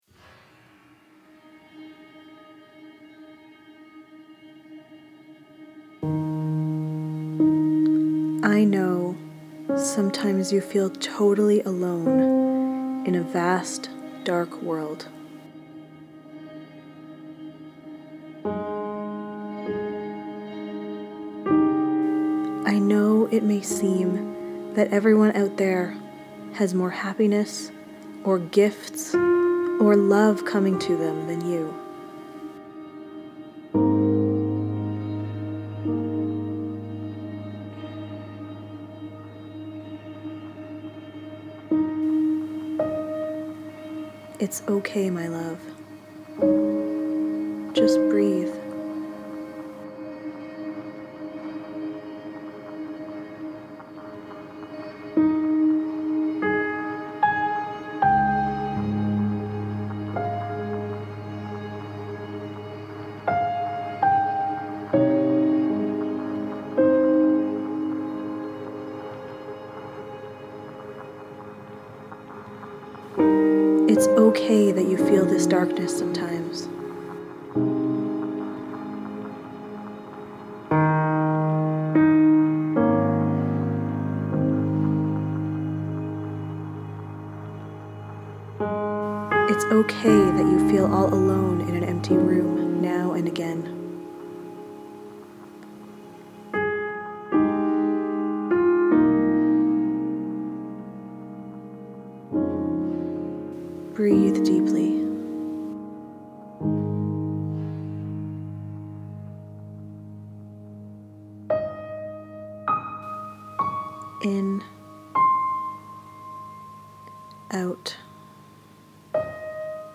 Meditation-for-loneliness-and-isolation.mp3